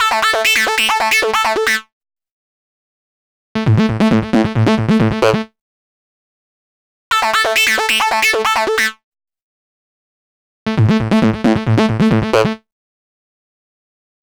Session 04 - Trance Lead 01.wav